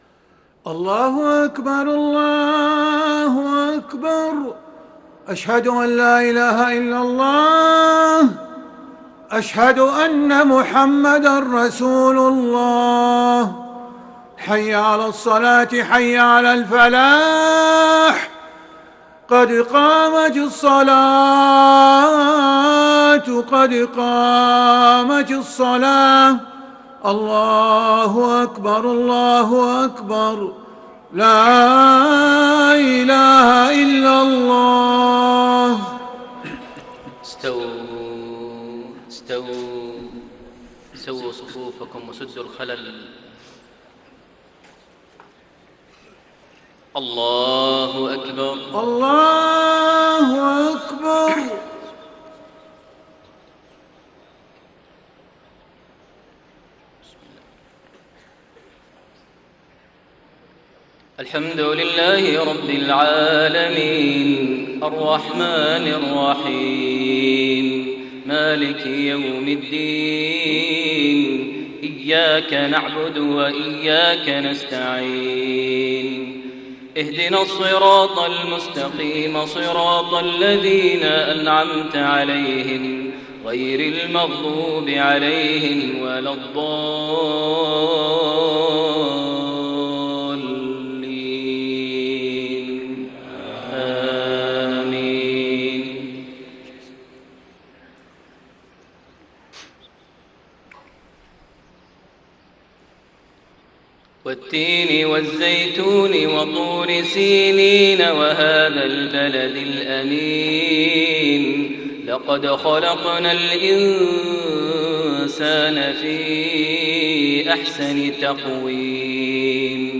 صلاة المغرب 15 ذو القعدة 1432هـ سورتي التين و النصر > 1432 هـ > الفروض - تلاوات ماهر المعيقلي